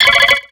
Cri de Charmillon dans Pokémon X et Y.